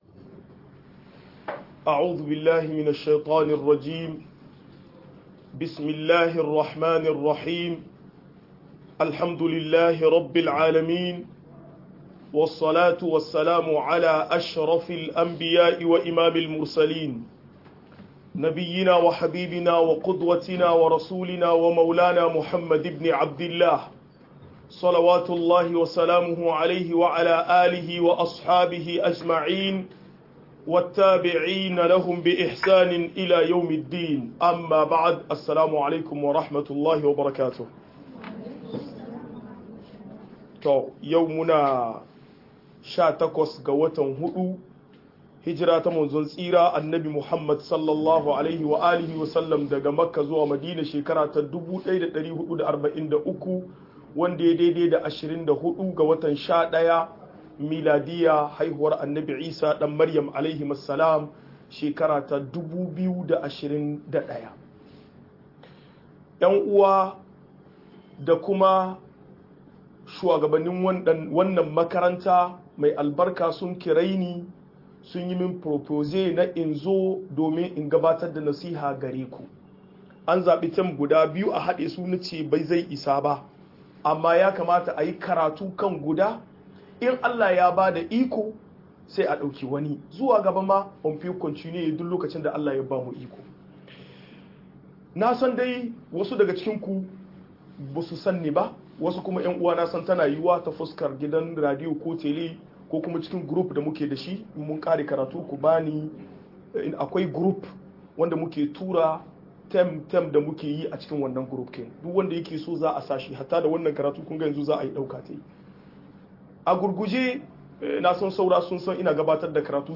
Muhimmancin mahaifiya a rayuwa - MUHADARA